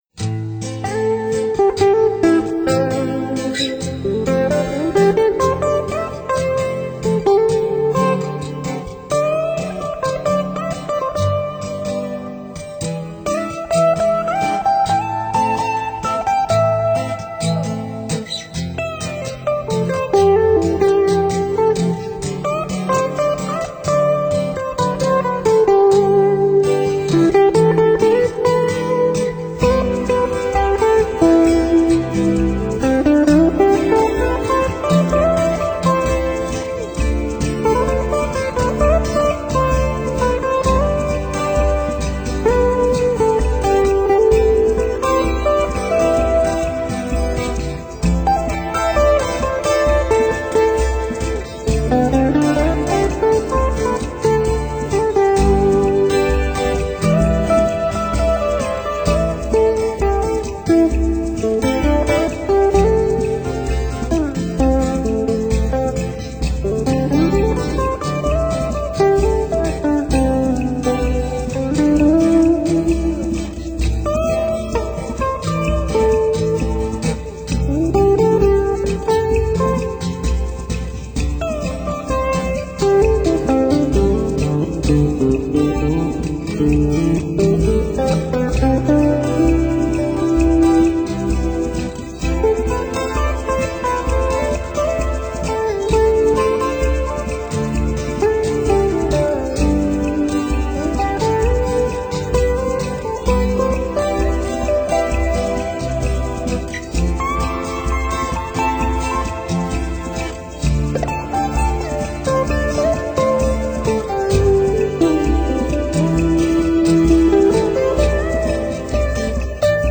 清扬欢快，明丽晓畅，打击乐清脆，鼓点节奏鲜明。